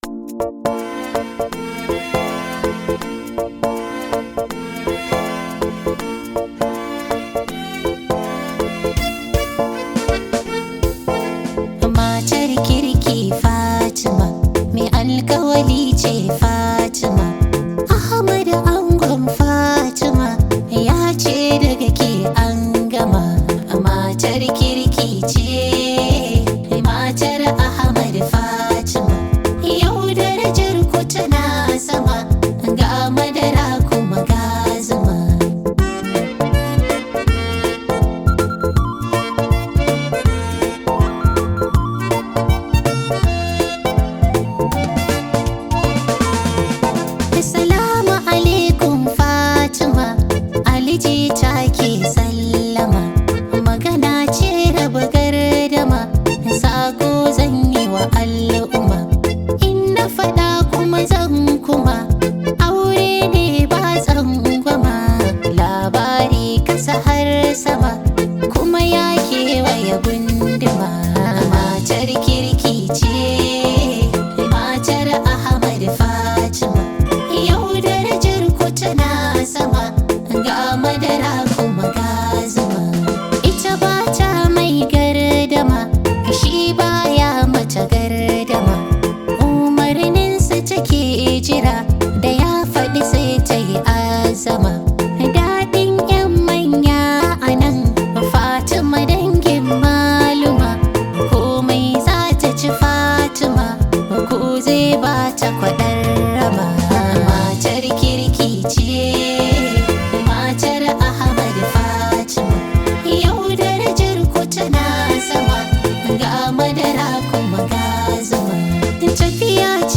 Hausa Songs
an Arewa rooted song